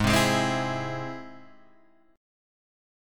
G# Augmented